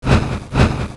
Heroes3_-_Gold_Dragon_-_MoveSound.ogg